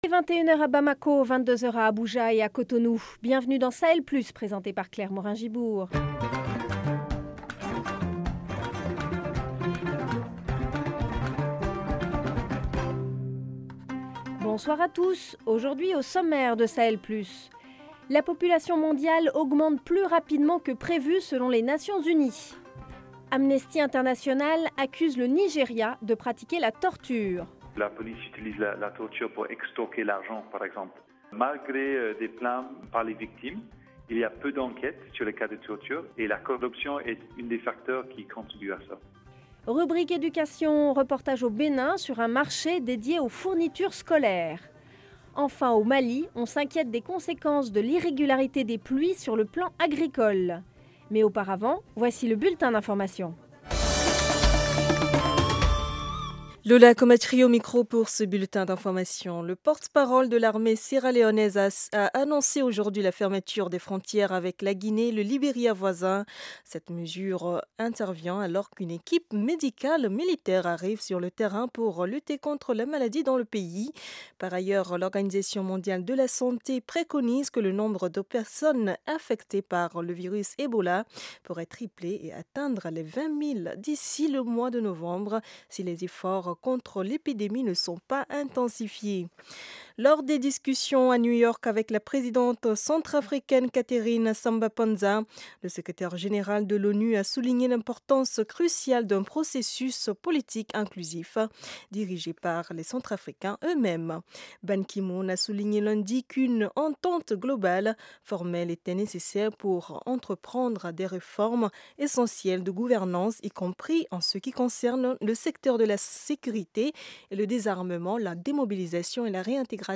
Au programme : la population mondiale augmente plus rapidement que prévu. Amnesty International accuse le Nigéria de pratiquer la torture. Reportage au Benin sur un marché dédié aux fournitures scolaires. Au Mali, on s’inquiète des conséquences de l’irrégularité des pluies sur le plan agricole.